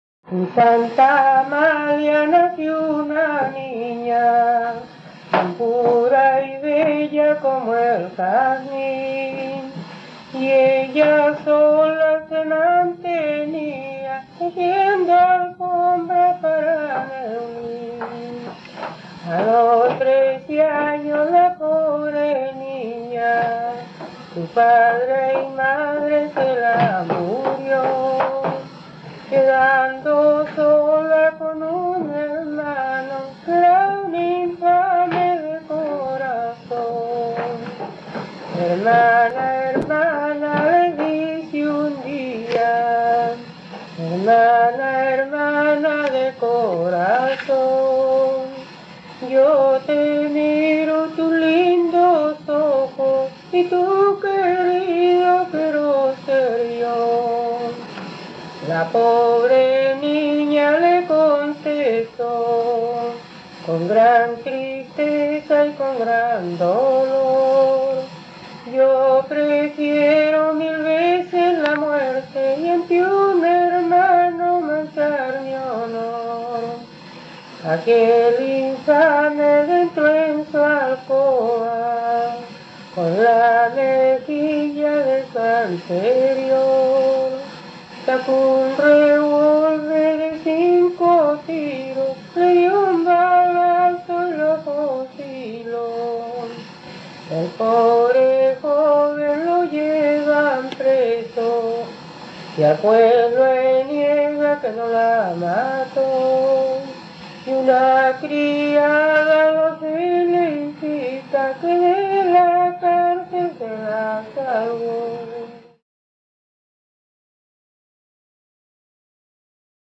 Romance tradicional en forma de canción.
Música tradicional
Folklore